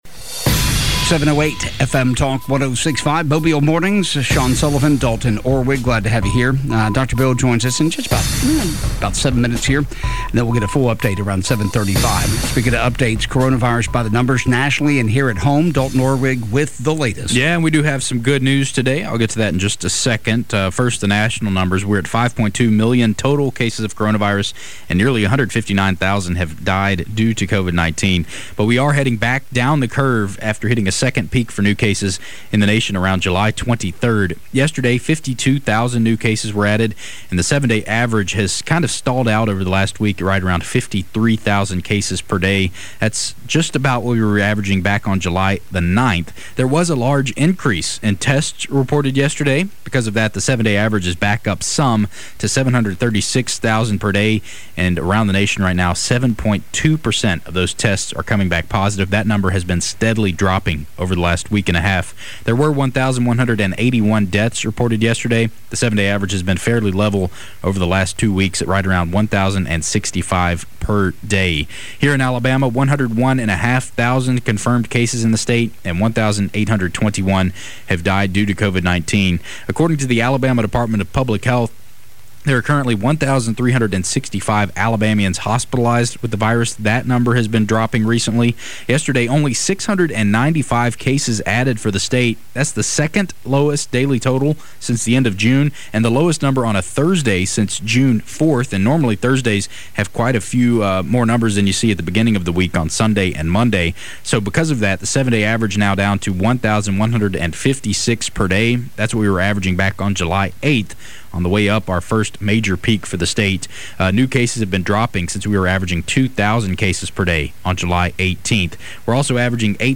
report on local news and sports